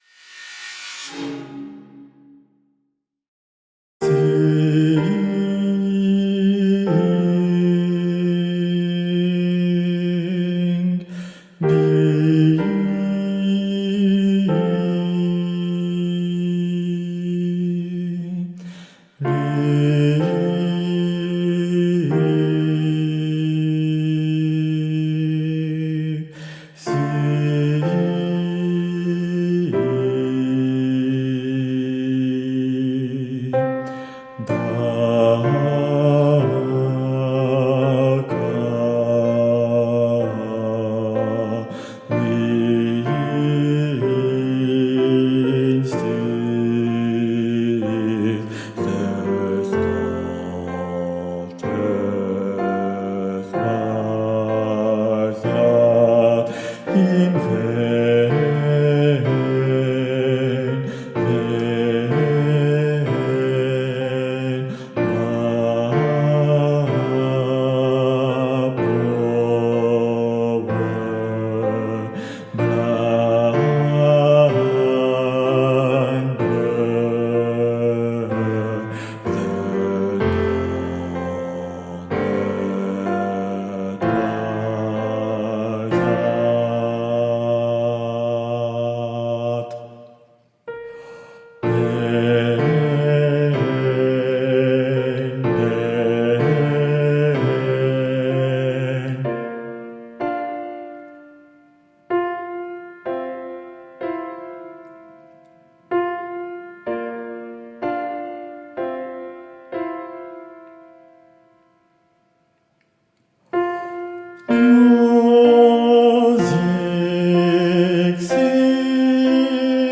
- Chant a capella à 4 voix mixtes SATB
Guide Voix Basses